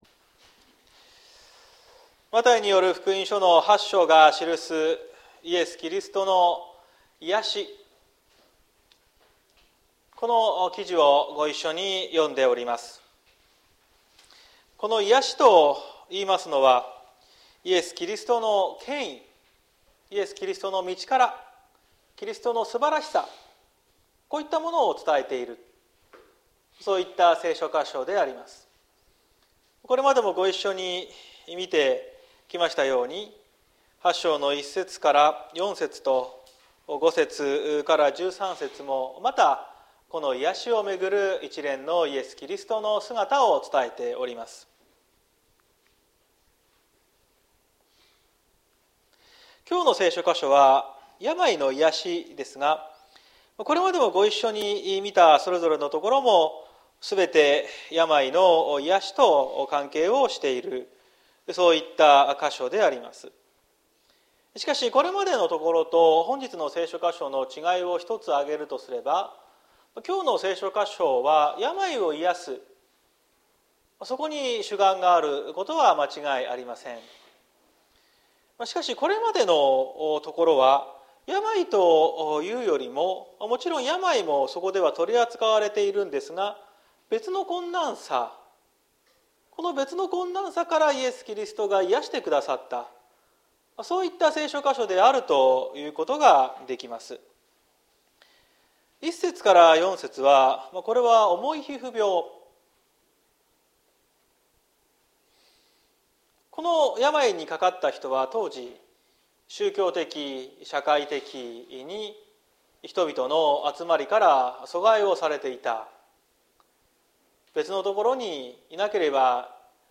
2023年06月18日朝の礼拝「キリストにいやしていただく」綱島教会
綱島教会。説教アーカイブ。